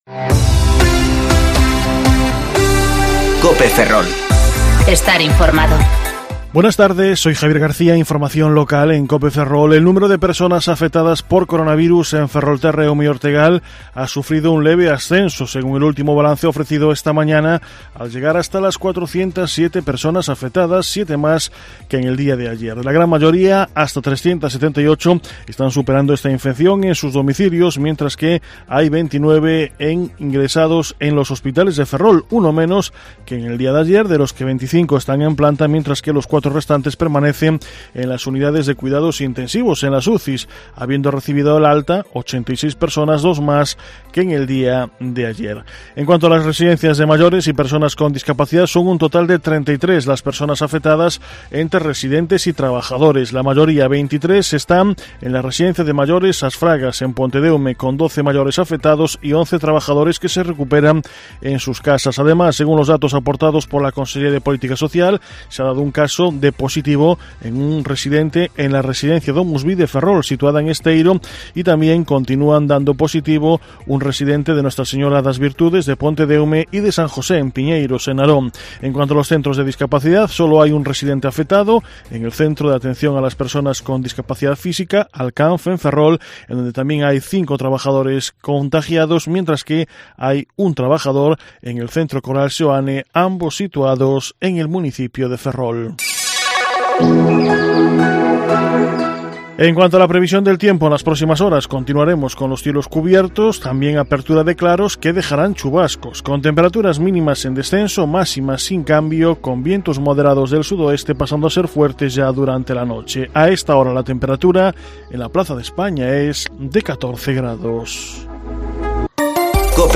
Informativo Mediodía COPE Ferrol - 28/4/2020 (De 14,20 a 14,30 horas)